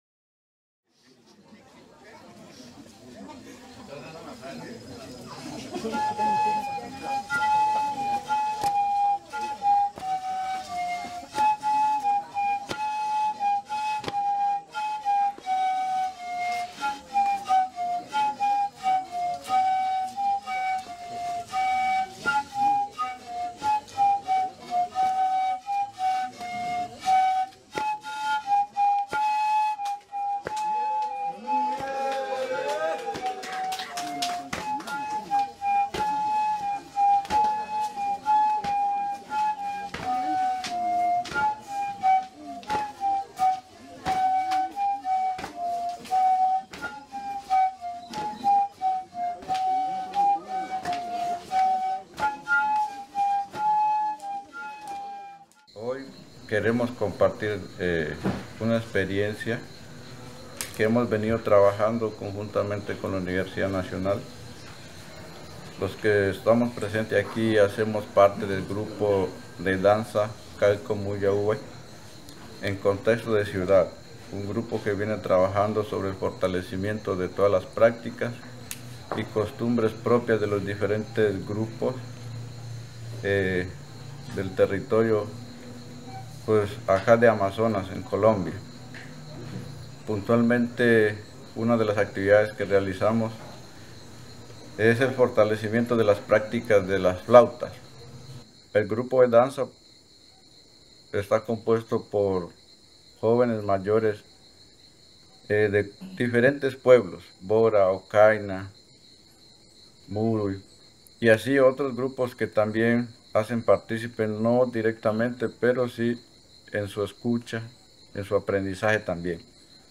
Leticia, Amazonas (Colombia)